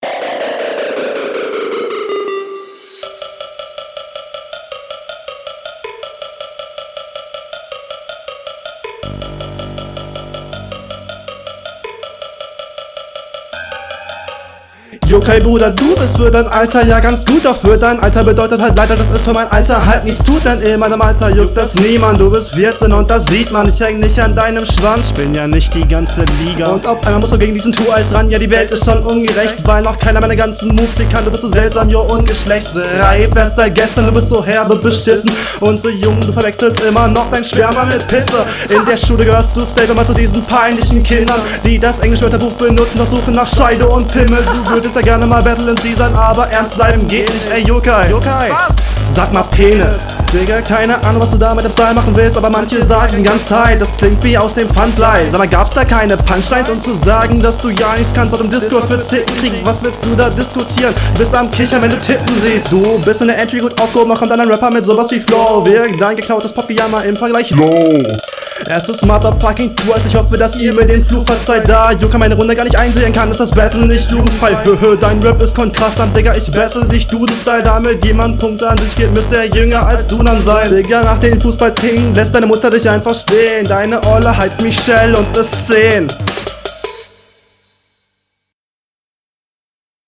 Alda dieser Beat VBT 2013 lässt grüßen.
Soundqualität: Wieder die selbe Soundqualität wie in der letzten Runde, aber (vielleicht liegts am beat?) …